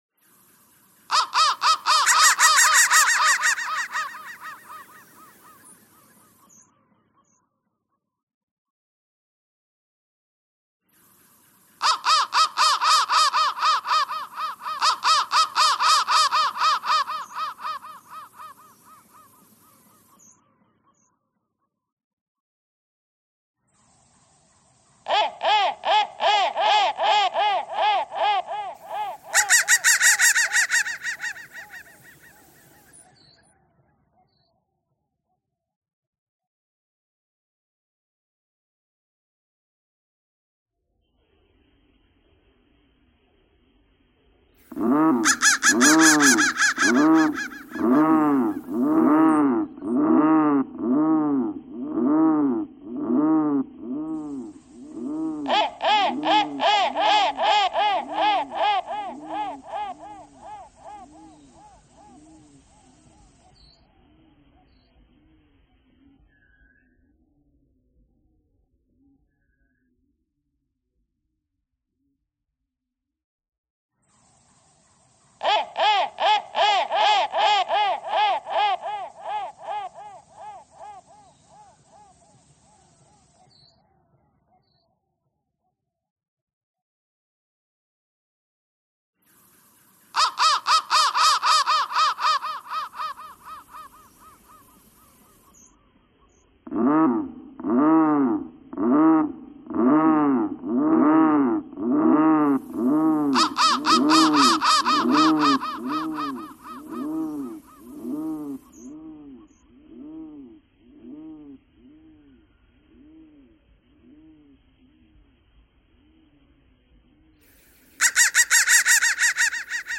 ::: an exercise in spinning thread from recorded sound fragments & then weaving these individual strands into twisted cords ::: an exercise in chance-infused, text-based, generative composition.